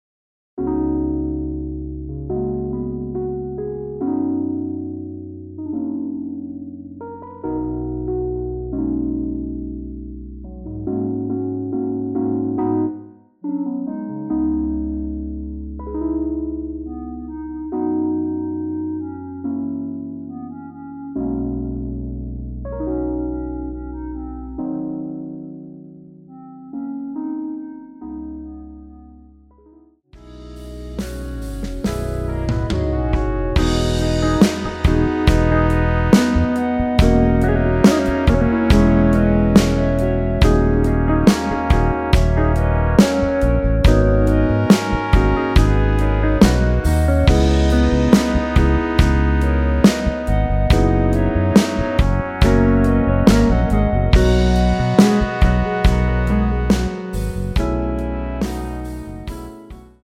원키에서 (-2)내린 멜로디 포함된 MR 입니다.
멜로디 MR이라고 합니다.
앞부분30초, 뒷부분30초씩 편집해서 올려 드리고 있습니다.
중간에 음이 끈어지고 다시 나오는 이유는